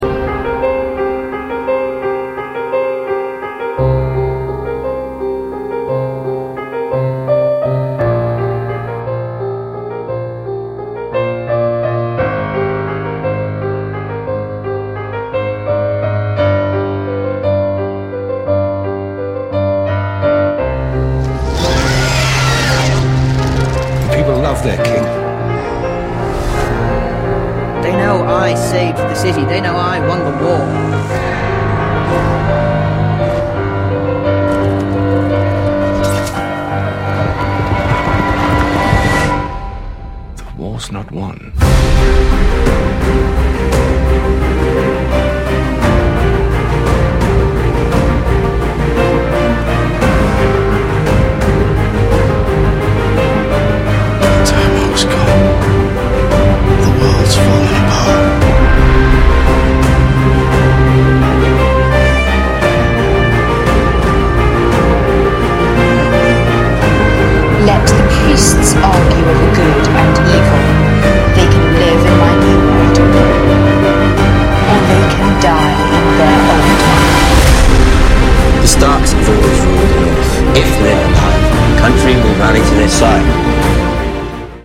نسخه پیانو